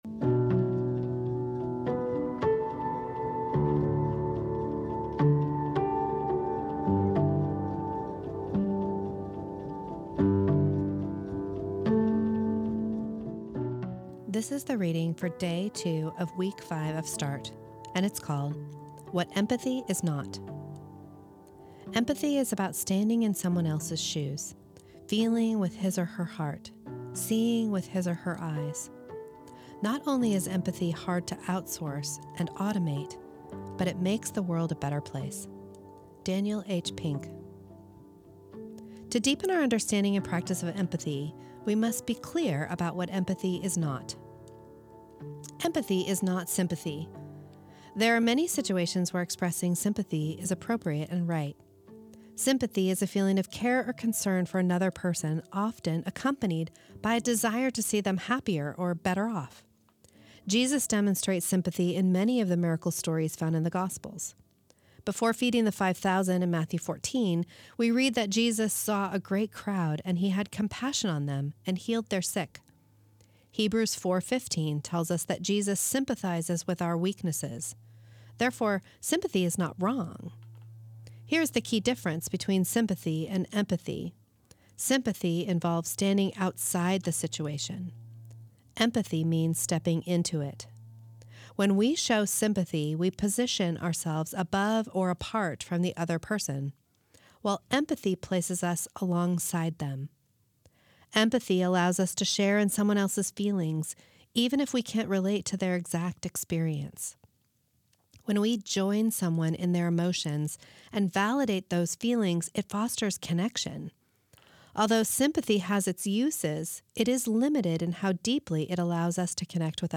This is the audio recording of the second reading of week seven of Start, entitled What Empathy is Not.